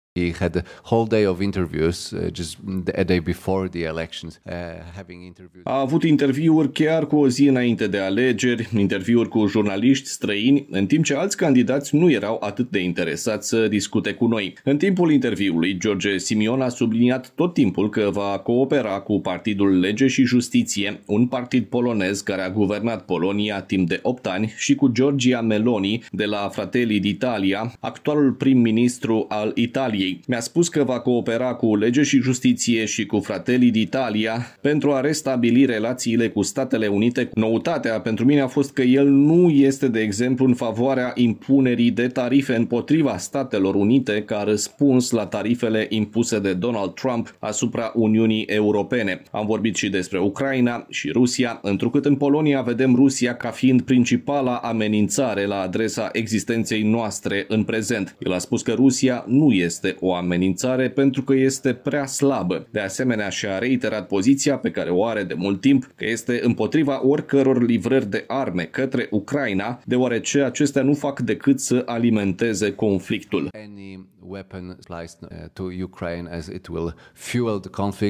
Într-un interviu acordat jurnalistului polonez, Simion a afirmat că dorește să colaboreze cu Partidul Lege și Justiție din Polonia și cu Fratelli d’Italia, formațiunea premierului italian Giorgia Meloni, într-o încercare de relansare a relațiilor cu Statele Unite: